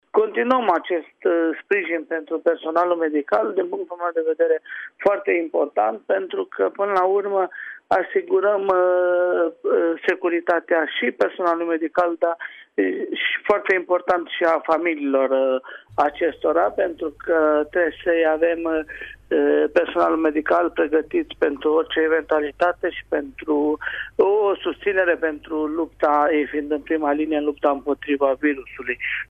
50 de camere de hotel sunt puse la dispoziție și în această perioadă, pentru cadrele medicale de la Spitalul Județean din Timișoara, în contextul problemelor generate de coronavirus. Medicii și asistenele primesc și trei mese calde pe zi, după cum spune președintele Consiliului Județean Timiș, Călin Dobra.